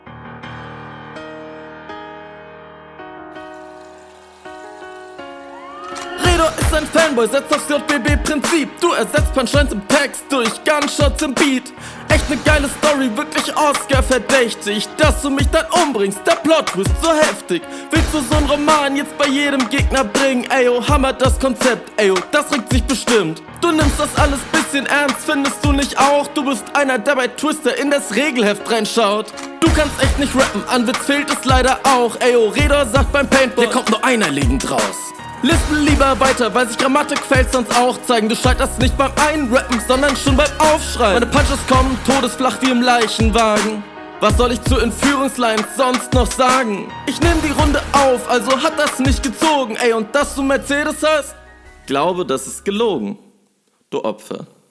Top S laute immer noch n bissl Scharf, aber passt Ja Ligen dem Gegner Vorraus